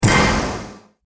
sfx updates